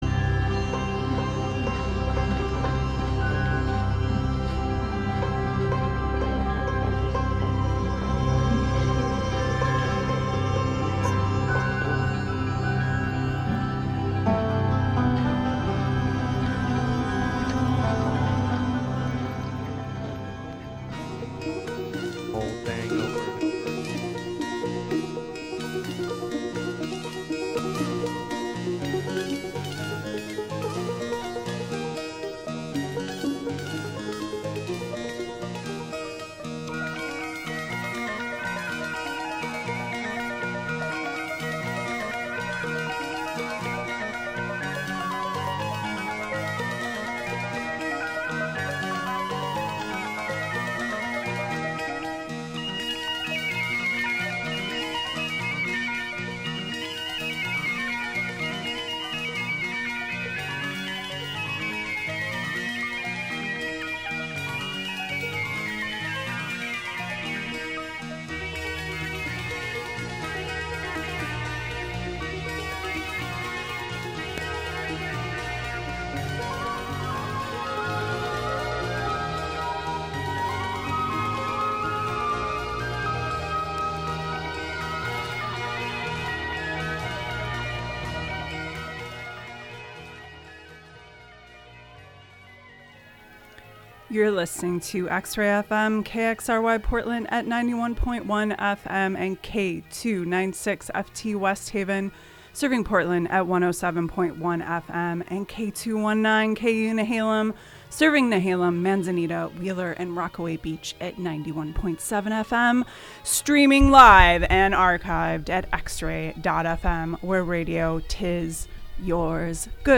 Medieval / early music / dungeon synth / cursed metal / fantasy film OSTs